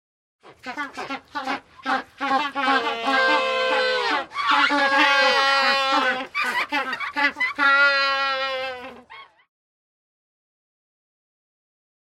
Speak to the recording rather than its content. • Quality: High